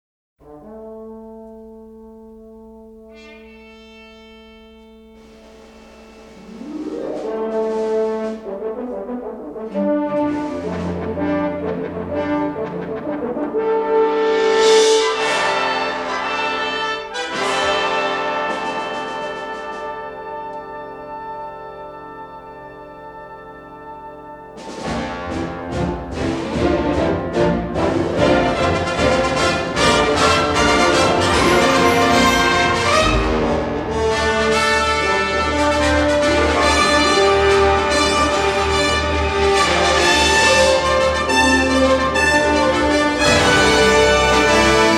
and a classic symphonic score.